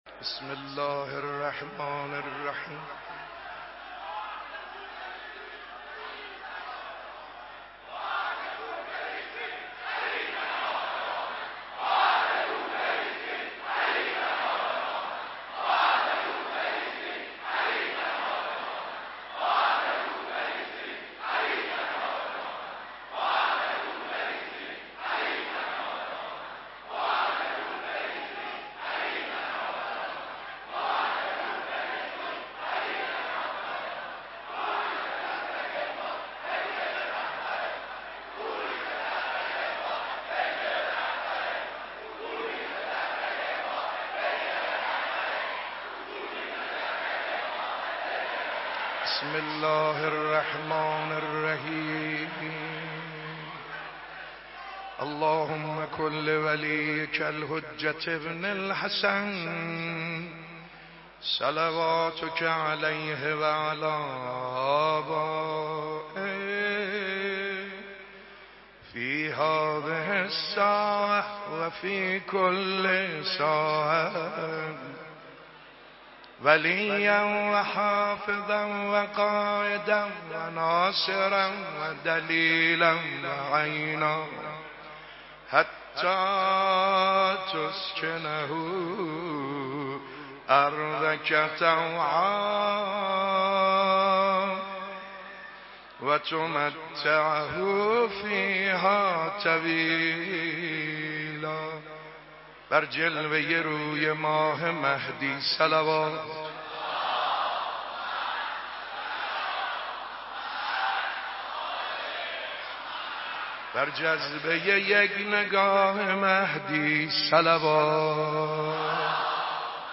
صوت/ مداحی حاج سعيد حداديان در حضور رهبرانقلاب
آخرین شب مراسم عزاداری بانوی دو جهان حضرت فاطمه‌ی زهرا سلام‌الله‌علیها در حسینیه‌ی امام خمینی رحمه‌الله امشب (جمعه)، با حضور رهبر انقلاب اسلامی، جمعی از مسئولان کشور و مردم دلداده‌ی اهل‌بیت عصمت و طهارت علیهم‌السلام برگزار شد.
نوحه